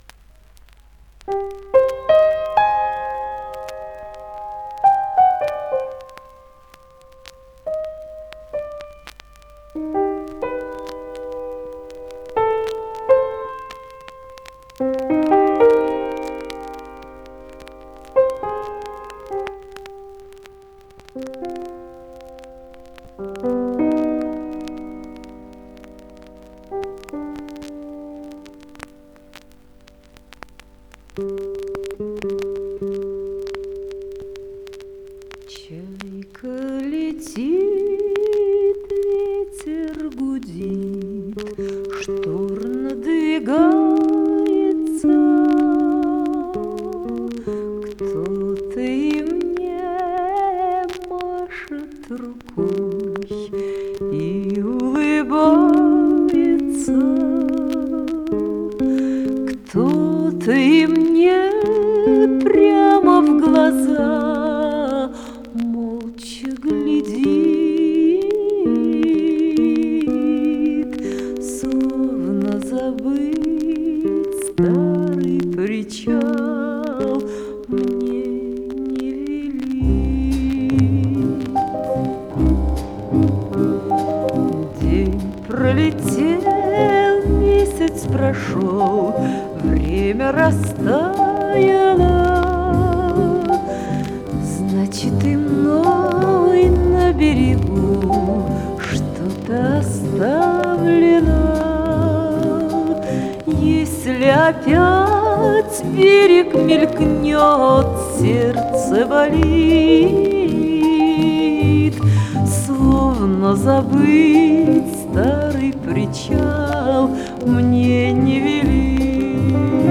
эта же песня без шумодава с винила 1965